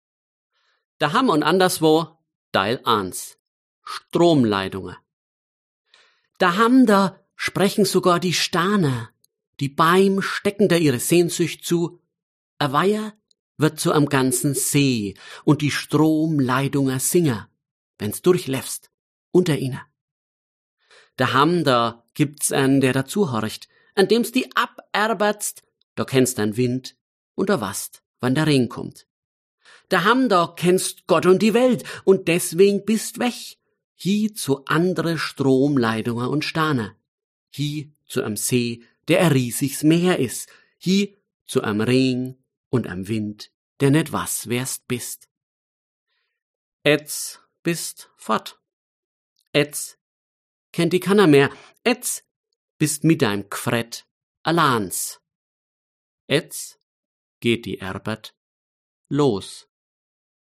Deshalb habe ich in den 31 Gedichten und Geschichten zwei Zungenschläge gewählt, die ich aus meiner Kindheit kenne: den weicheren, eher ländlich geprägten meiner Mutter und den härteren, städtischen meines Vaters.
Da diese zwei Stimmen in mir sprechen und aus mir herauswollen, habe ich in den Geschichten eher die Aussprache meines Vaters zu Wort kommen lassen, in den Gedichten eher den Dialekt meiner Mutter.